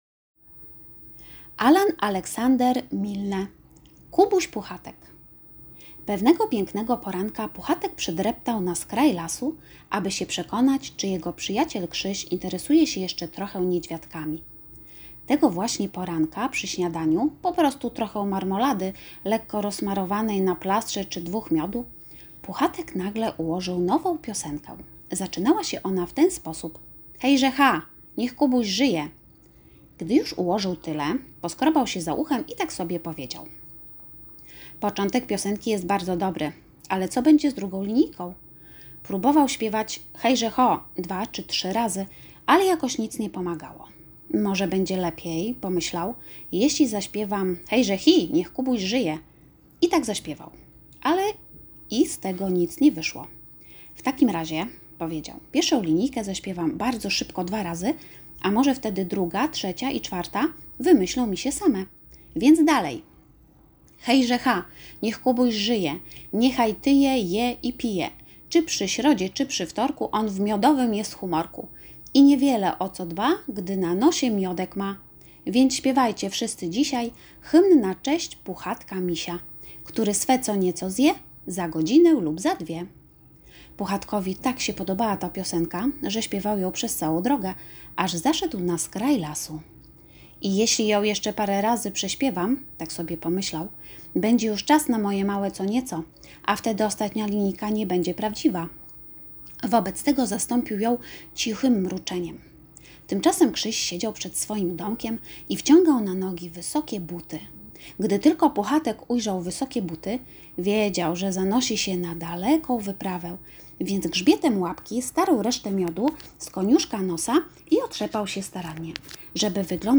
Wysłuchajcie fragmentu książki A.A.Milne „Kubuś Puchatek”, a potem poznajcie propozycje zabaw z książką uwielbianą przez małych i dużych.